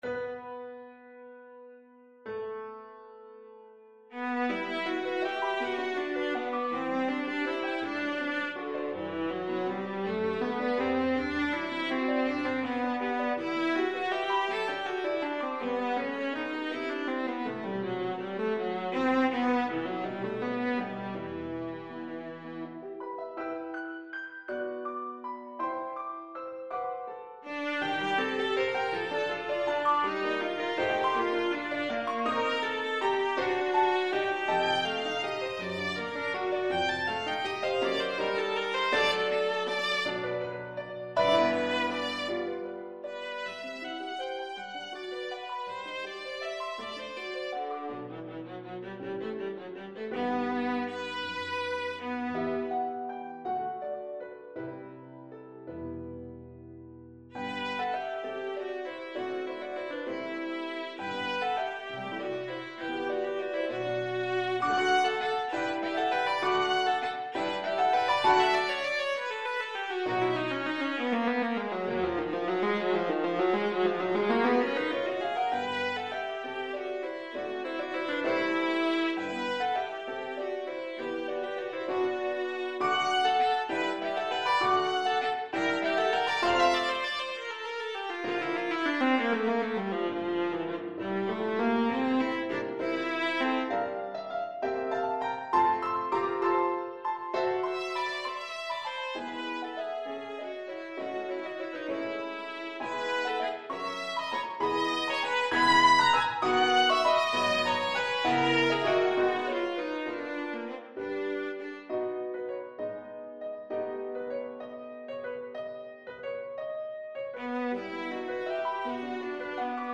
Viola
~ = 100 Allegro moderato .=54 (View more music marked Allegro)
D4-B6
6/8 (View more 6/8 Music)
E minor (Sounding Pitch) (View more E minor Music for Viola )
gaubert_sicilienne_VLA.mp3